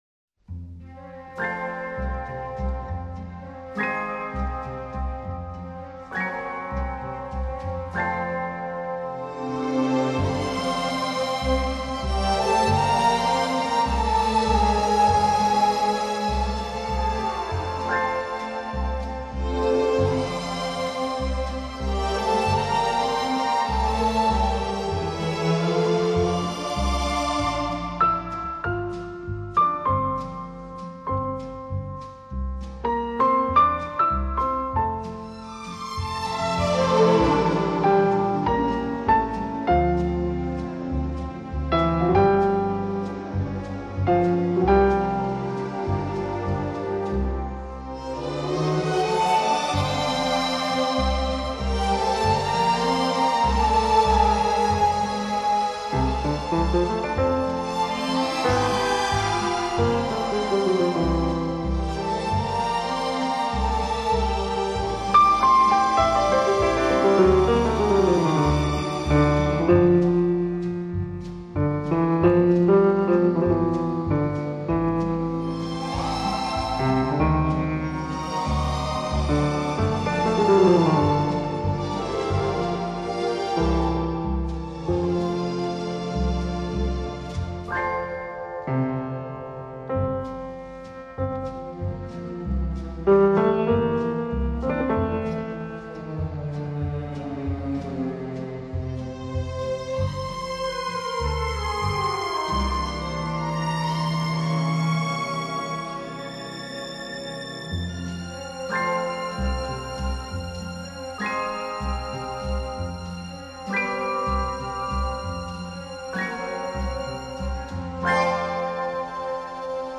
【轻音乐专辑】
录制方式：ADD